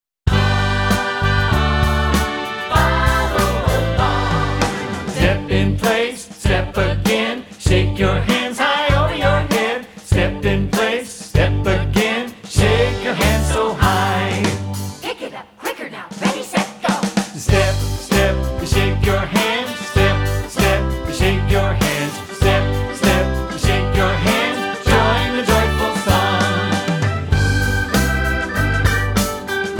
A movement and counting song.